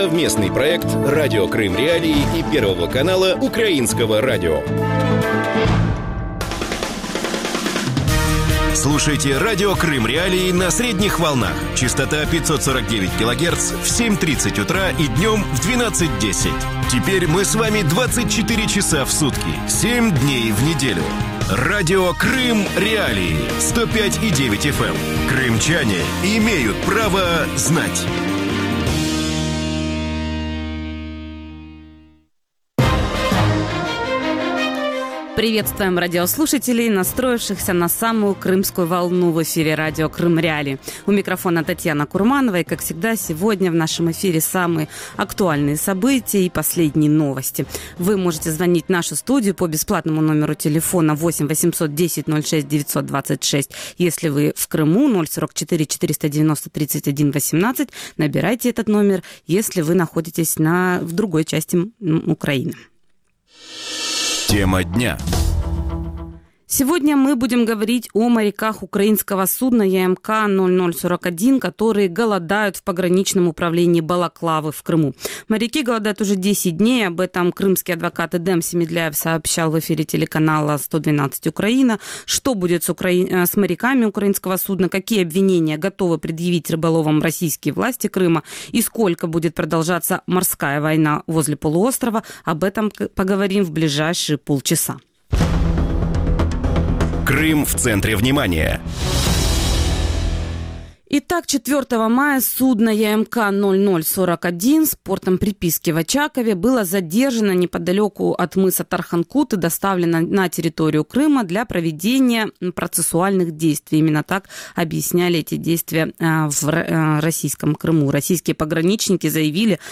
Радио Крым.Реалии в эфире 24 часа в сутки, 7 дней в неделю.